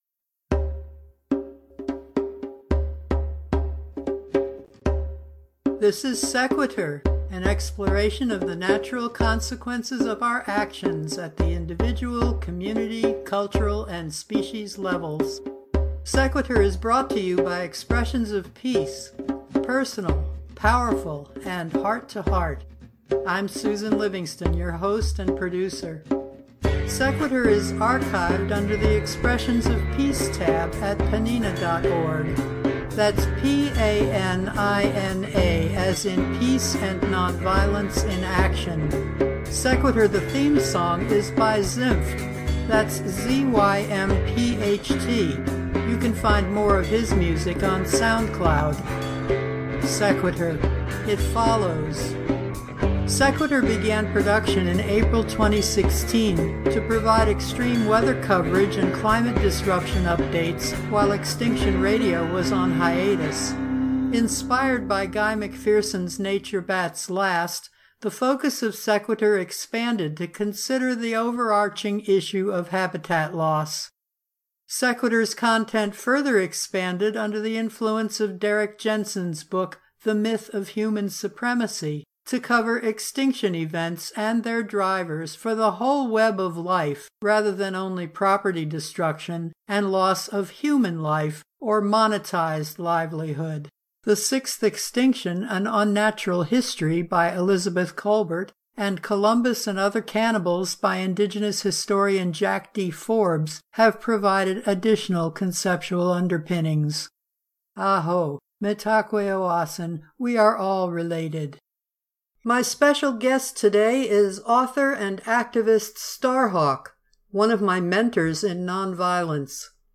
In the featured interview, Starhawk connects the dots between permaculture design, social and environmental activism, and pagan spirituality.